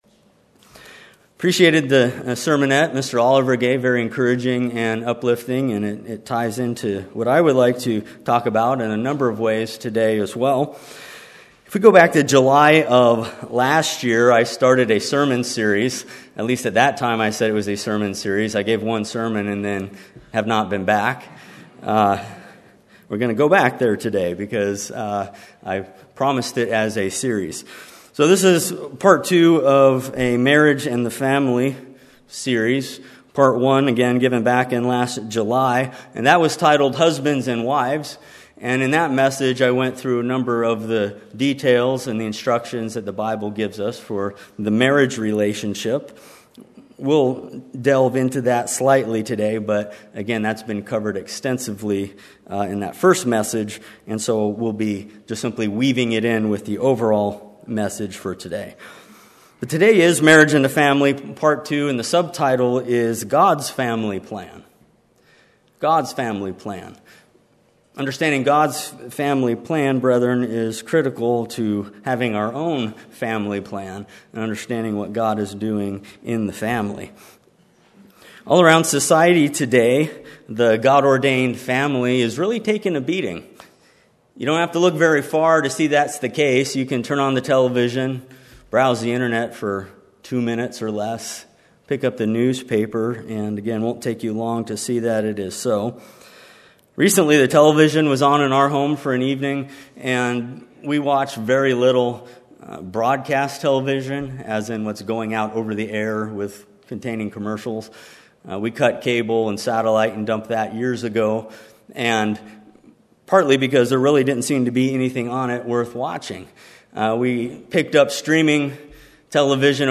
Physical families are the mechanism God is using to expand His spiritual family. This sermon shows that God must be central in the relationship between husband, wife, and children.